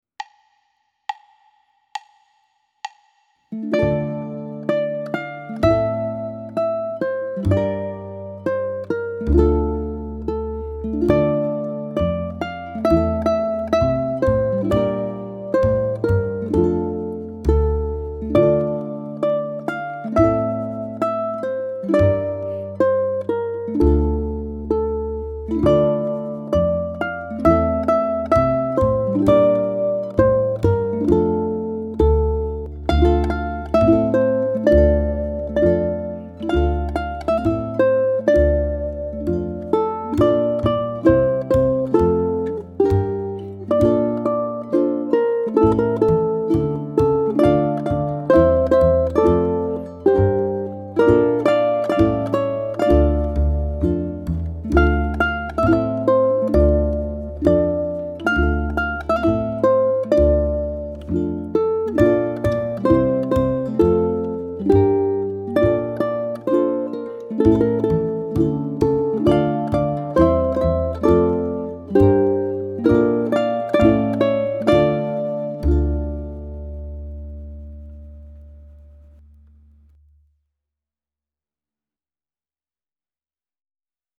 Linda Amiga is a hauntingly beautiful Spanish folk song full of lyrical melody, intense longing and melancholy modal progressions.
ʻukulele